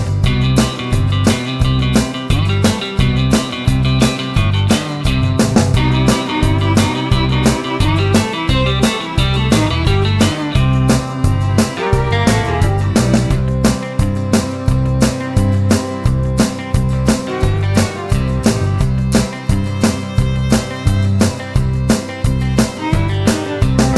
no Backing Vocals Country (Male) 2:24 Buy £1.50